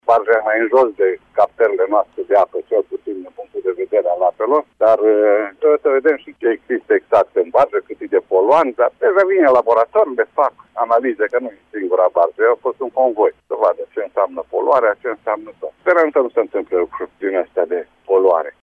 Indiferent de rezultat, locuitorii din Moldova Nouă vor fi puţin afectaţi, spune primarul Matei Lupu pentru că incidentul a avut loc în aval de zona de unde se captează apă pentru oraş: